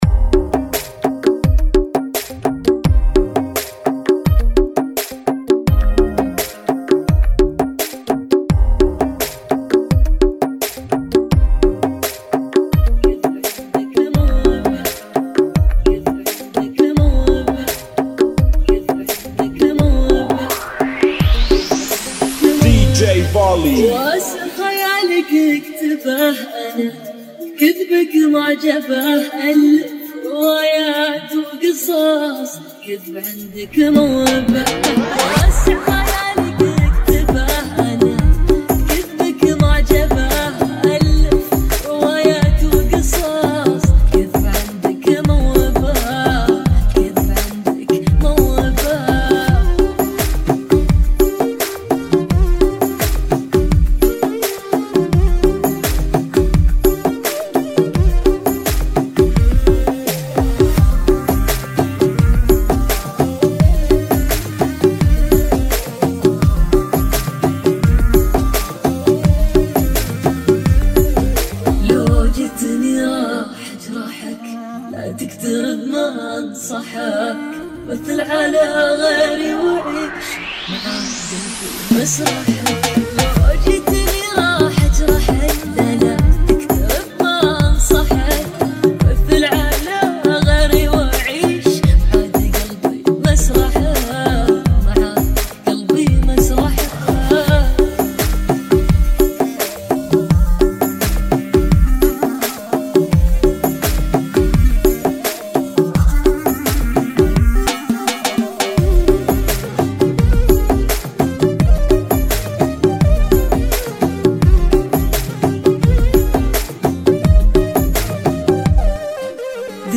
85 BPM